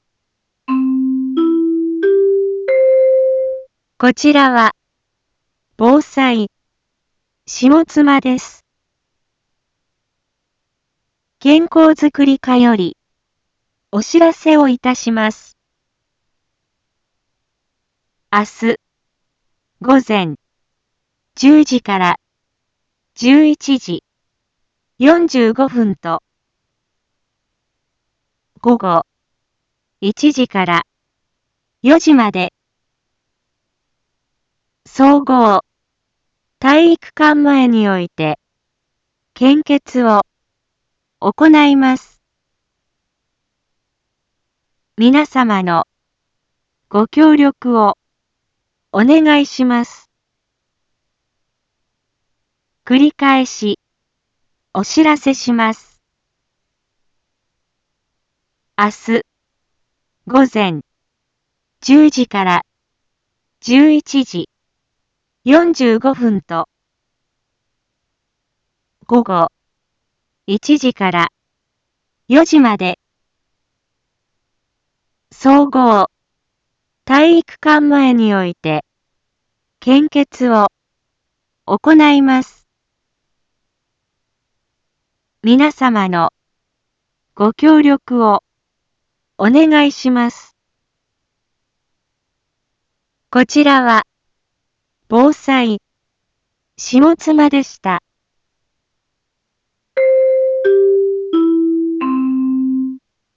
一般放送情報
Back Home 一般放送情報 音声放送 再生 一般放送情報 登録日時：2024-03-13 18:31:49 タイトル：【前日報】献血のお知らせ インフォメーション：こちらは、防災、下妻です。